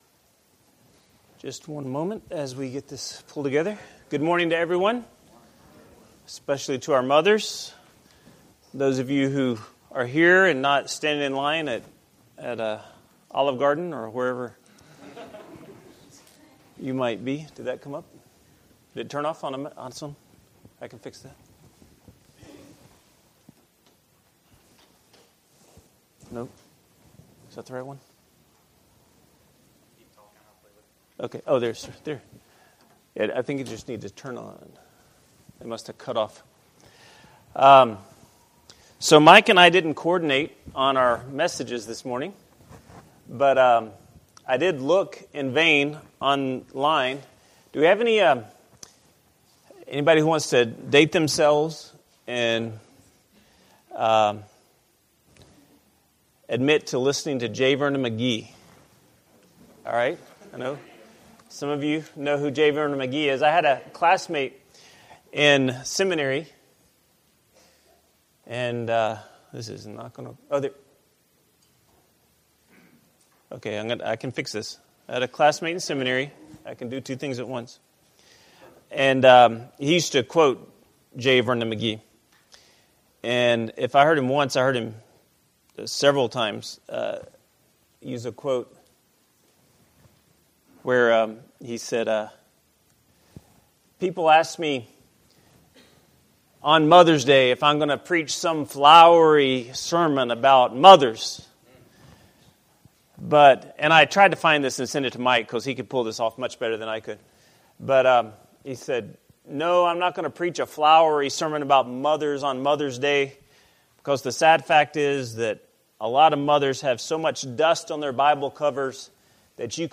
Adult Bible Study